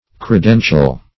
Credential \Cre*den"tial\ (kr[-e]*d[e^]n"shal), a. [Cf. It.